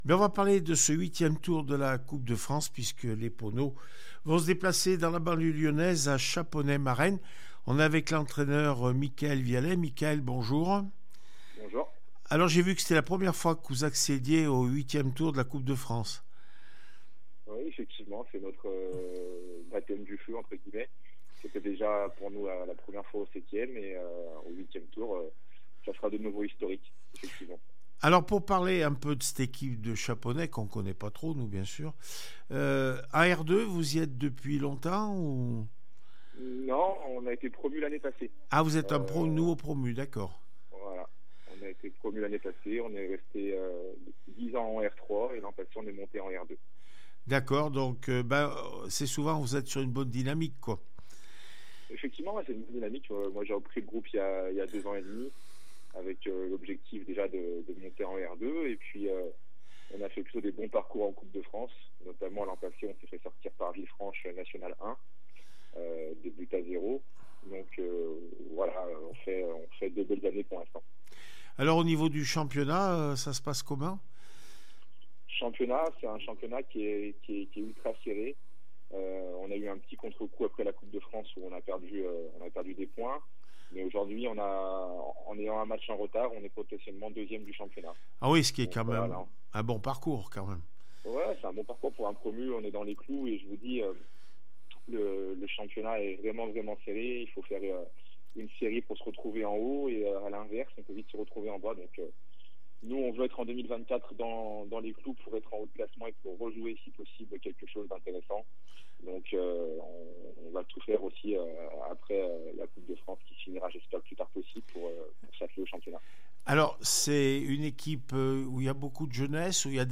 8 décembre 2023   1 - Sport, 1 - Vos interviews